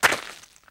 STEPS Gravel, Walk 06.wav